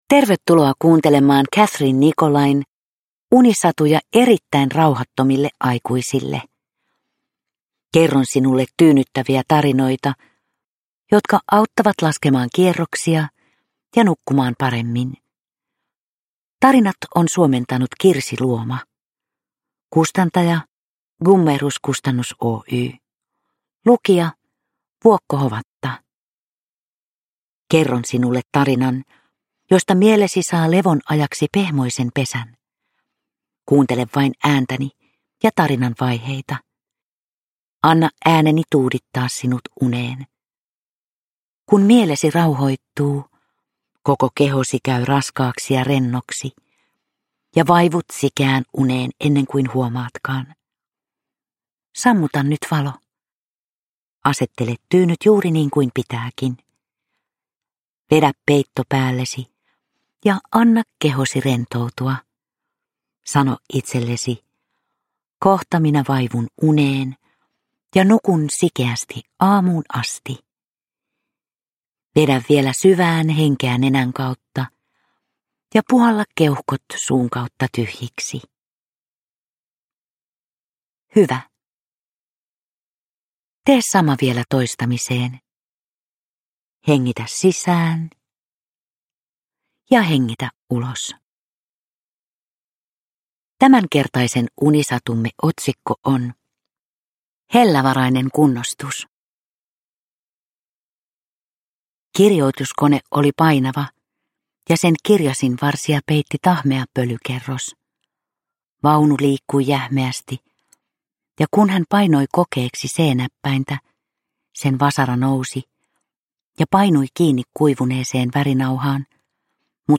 Vuokko Hovatan tyyni ääni saattelee kuulijan lempeästi unten maille.
Uppläsare: Vuokko Hovatta